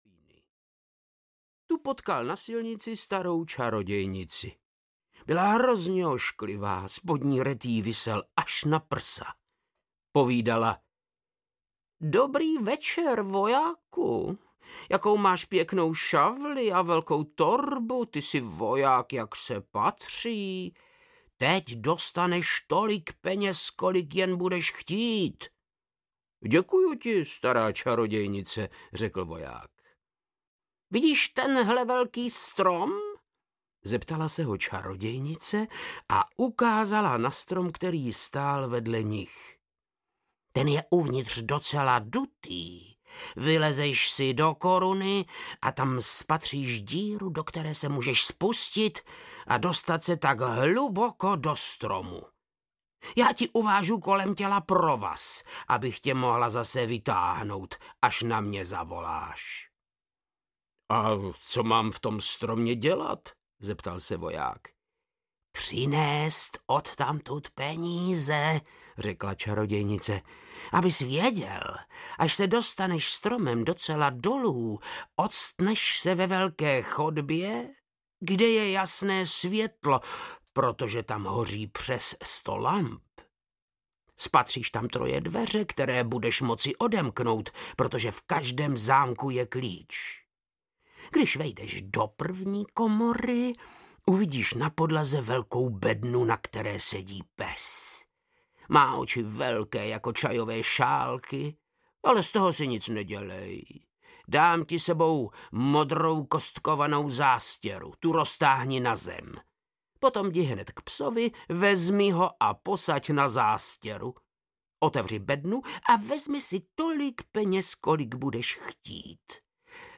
Křesadlo audiokniha
Ukázka z knihy
• InterpretVáclav Knop